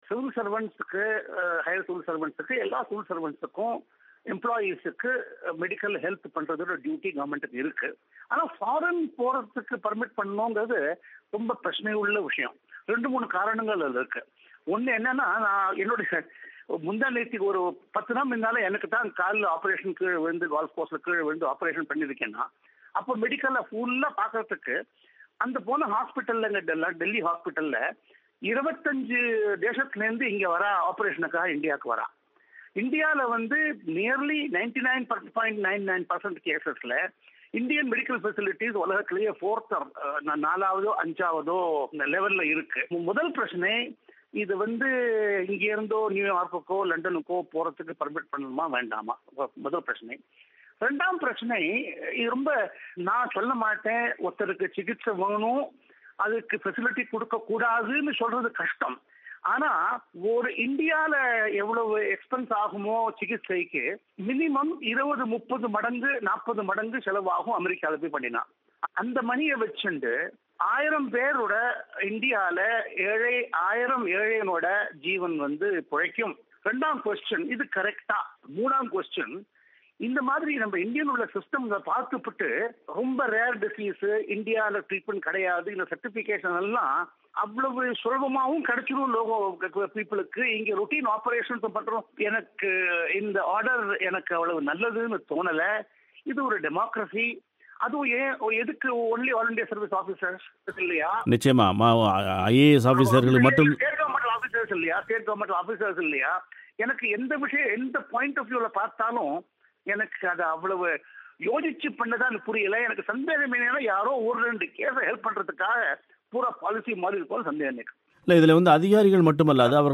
இந்திய அரசு, அகில இந்தியப் பணி அதிகாரிகளுக்கும் அவர்கள் குடும்பத்தினருக்கும் மருத்துவ சிகிச்சைக்காக வெளிநாடு செல்ல ஆகும் செலவை அரசு ஏற்கும் என்ற அறிவிப்பு சர்ச்சையை ஏற்படுத்தியிருக்கிறது. இது குறித்து ஓய்வு பெற்ற மத்திய அமைச்சரவைச் செயலர் டி.எஸ்.ஆர்.சுப்ரமண்யம் அவர்களின் பேட்டி